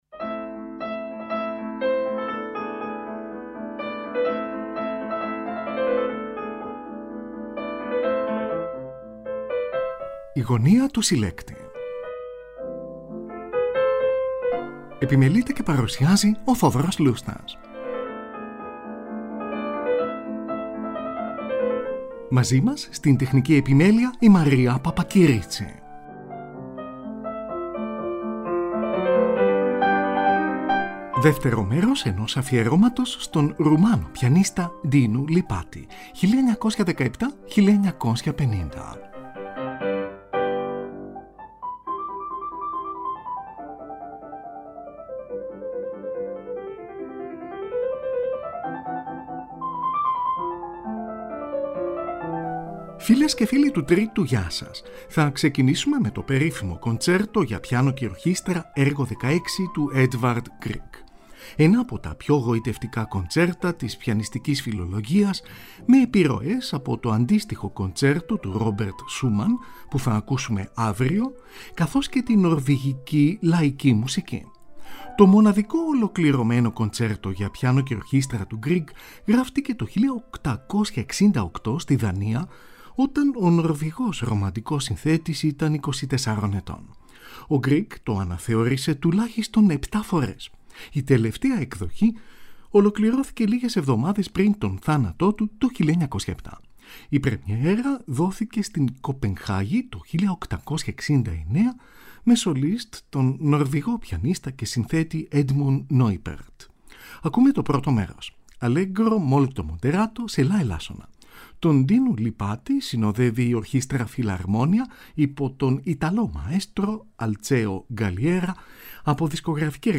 κοντσέρτο για πιάνο και ορχήστρα
βαρκαρόλα
σονάτες για τσέμπαλο